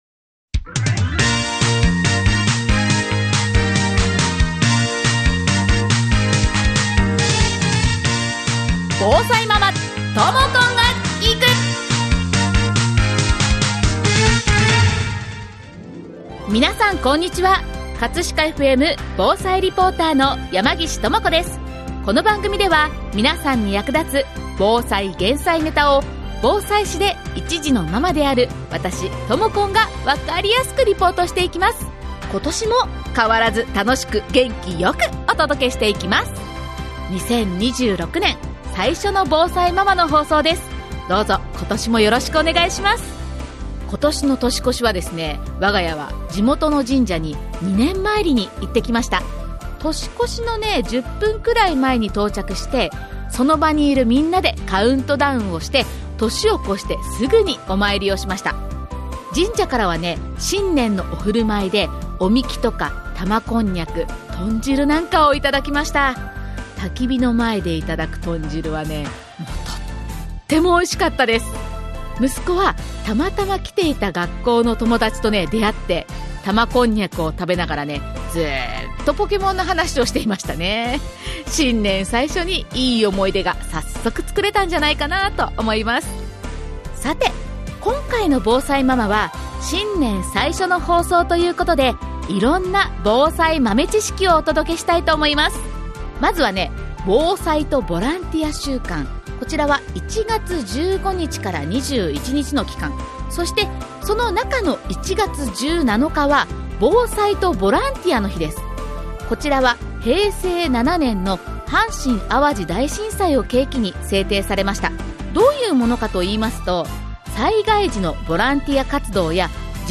2026年最初の防災ママということで、今回はスタジオから防災豆知識をお届けします！